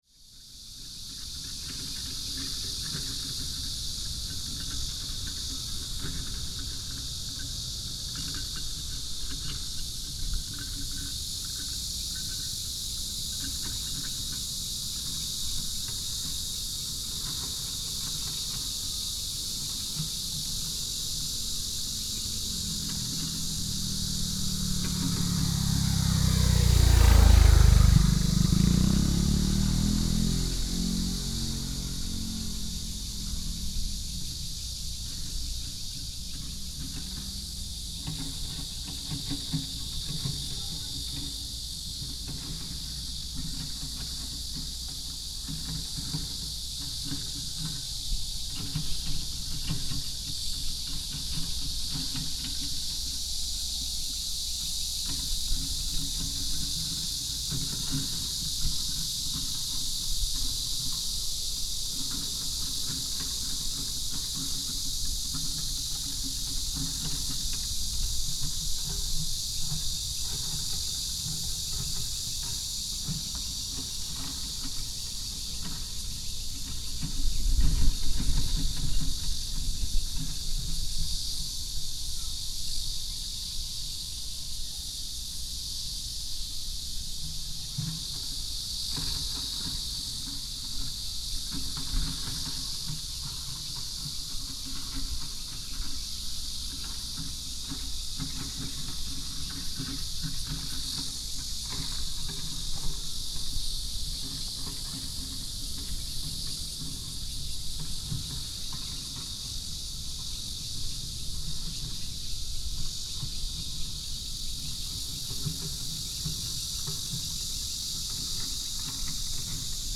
Bazilin, Dayuan Dist., Taoyuan City - At the corner of the roadside
At the corner of the roadside, in the small village,cicada,,Birds sound,Excavator,Binaural recordings ,Best with Headphone, Proposal to turn up the volume ,Sony PCM D100+ Soundman OKM II